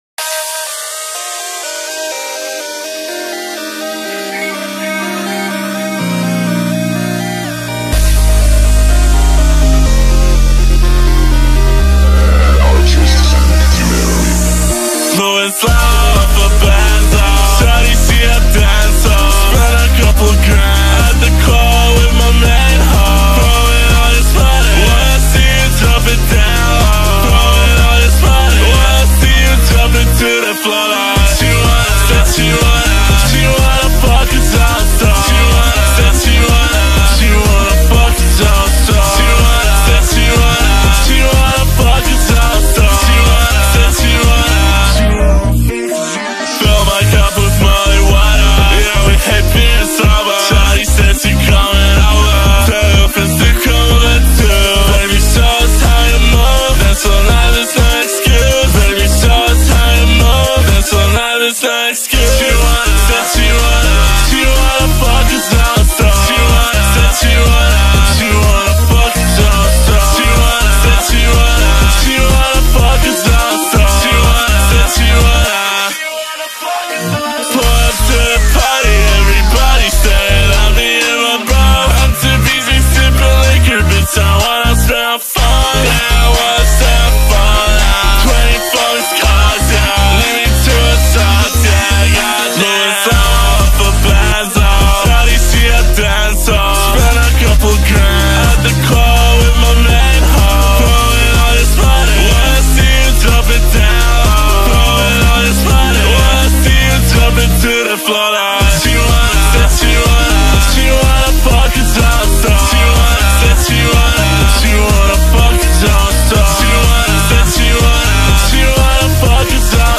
با ریتمی کند شده